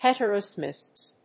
音節het・er・o・sex・ism 発音記号・読み方
/hèṭəroʊséksɪzm(米国英語)/